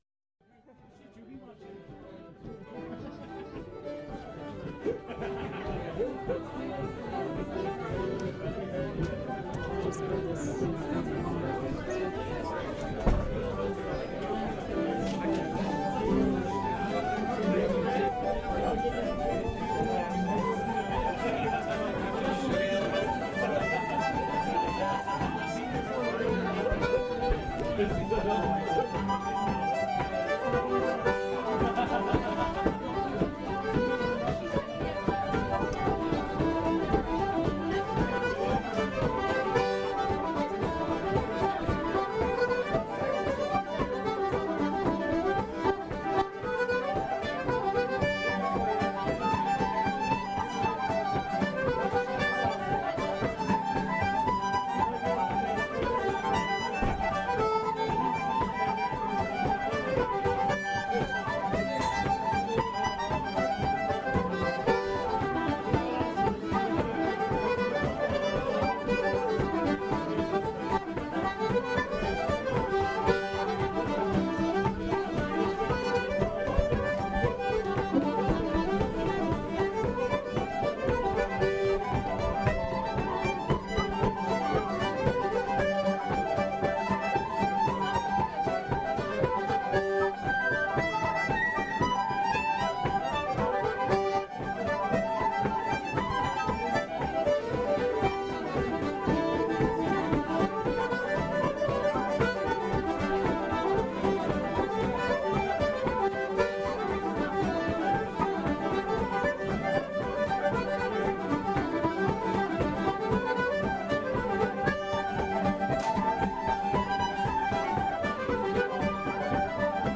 Lieu d'enregistrement : Pub Nancy Blakes (Limerick)
Nombre de musiciens : 5-7 musiciens
2 Reels